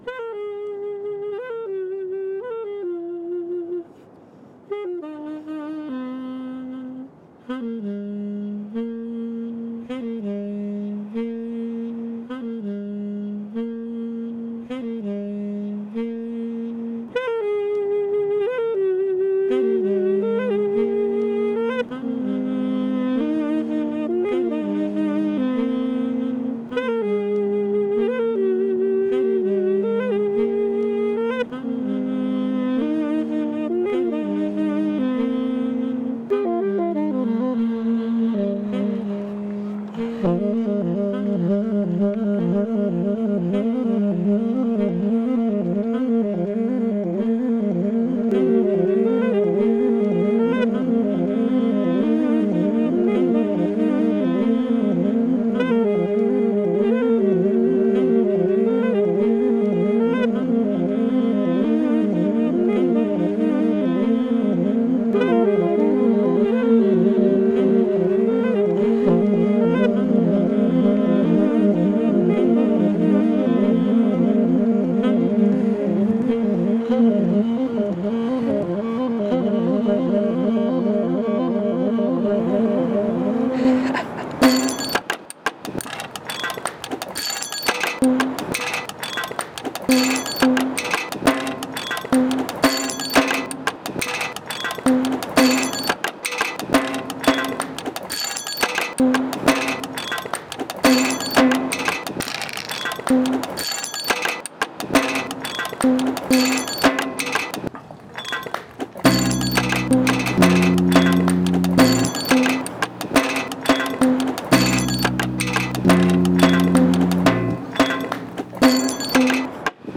collage music